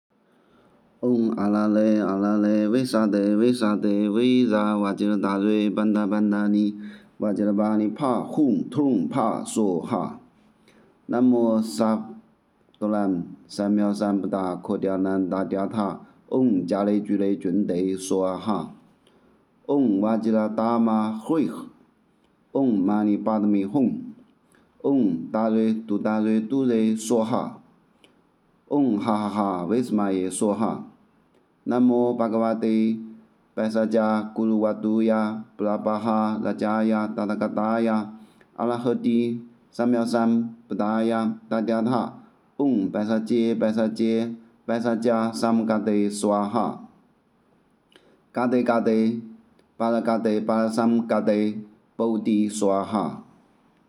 咒音范例
放慢0.6倍速的录音